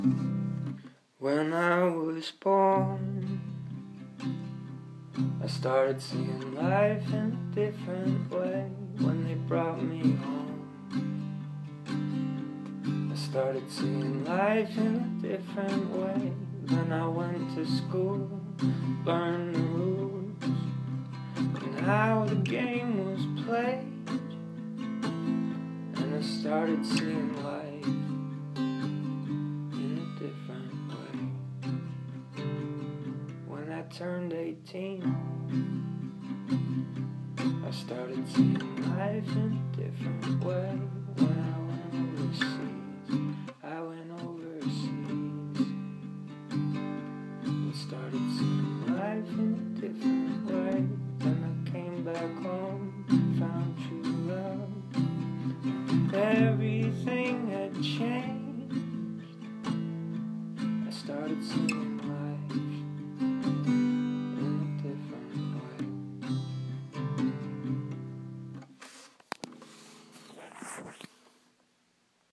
My mumbly and slow worktape.